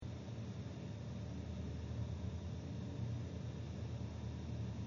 Vervolgens werd het geluid opgenomen aan de ontvangzijde voor de betonnen wand met voorzetwand en later ook zonder voorzetwand.
ontvang-zonder.jpg (876 bytes) signaal ontvangzijde zonder voorzetwand
Wanneer we de voorzetwand wegnemen, blijkt het geluiddrukniveau aan de ontvangzijde met ongeveer 10 dB toe te nemen.